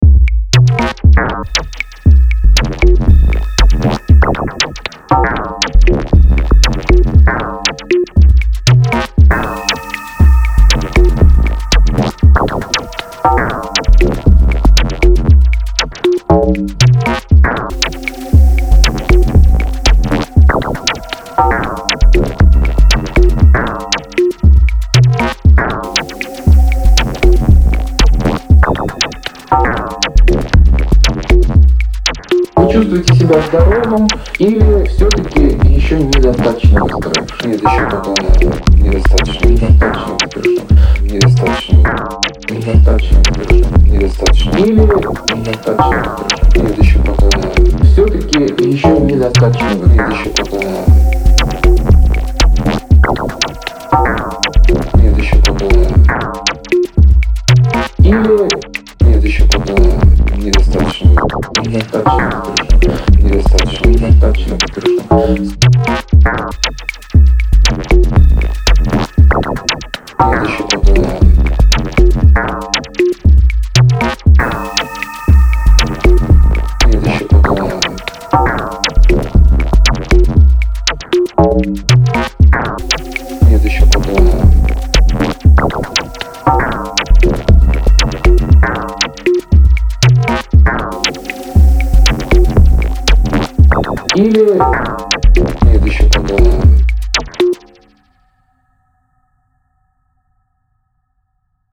а так в последние время для расположения в пространстве стал использовать технику ringmod sidechain она очень помогает раскинуть звуки при этом нет геморроя с конфликтами схожих по спетрку тембров .